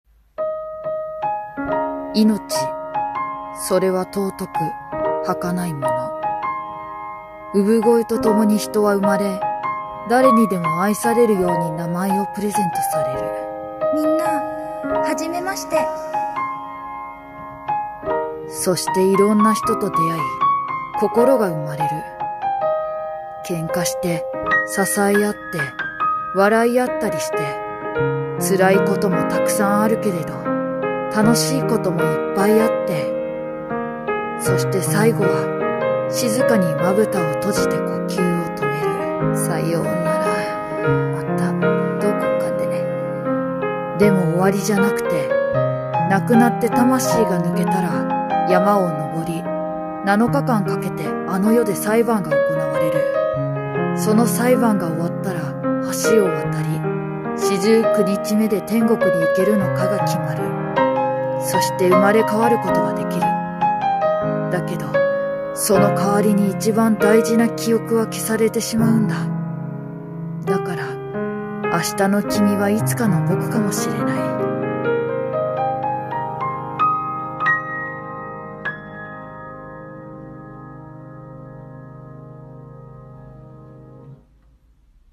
一人声劇